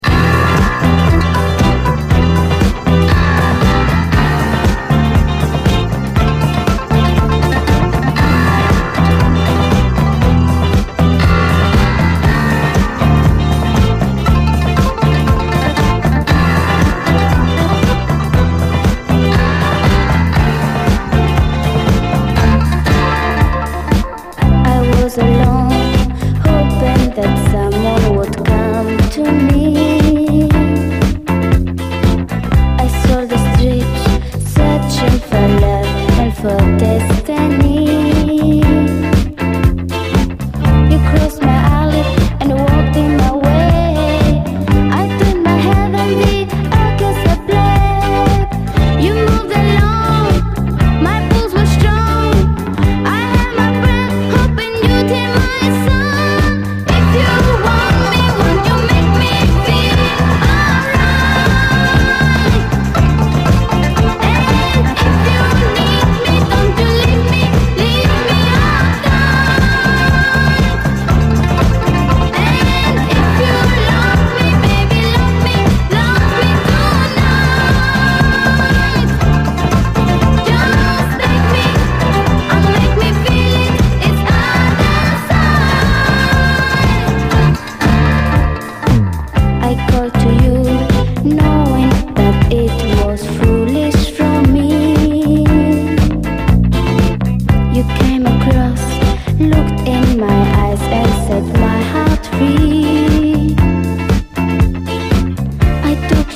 SOUL, 70's～ SOUL, DISCO
イタロ風のエレクトリック・アレンジがソソる、コケティッシュなユーロ・シンセ・ディスコ